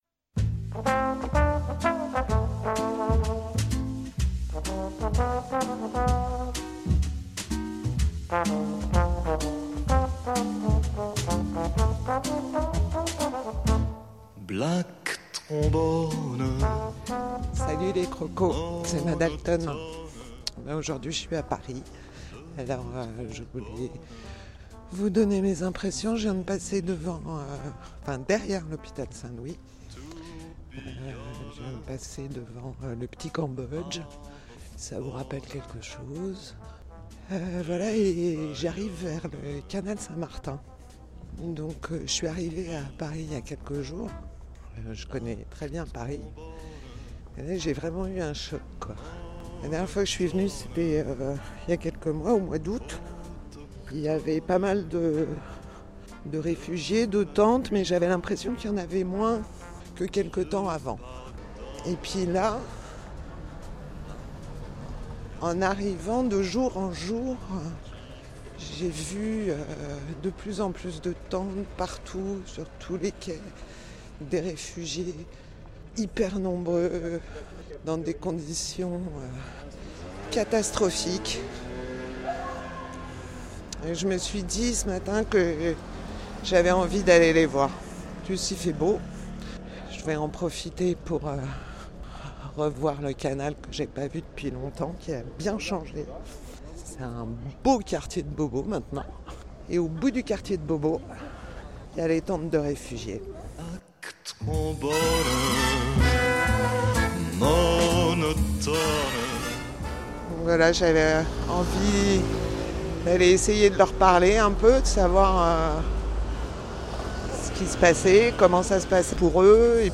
Reportage le 30 novembre 2016 à Paris aux alentours de Jaurès et Stalingrad.
Balade parisienne aux abords du canal St Martin et de la rue de Flandre. Rencontres avec des migrants, des habitants et des militants du BAAM (Bureau d’accueil et d’accompagnement des migrants).